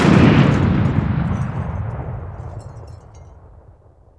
Index of /90_sSampleCDs/AKAI S6000 CD-ROM - Volume 1/SOUND_EFFECT/EXPLOSIONS
CRASH.WAV